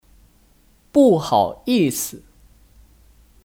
不好意思 (Bù hǎoyìsi 不好意思)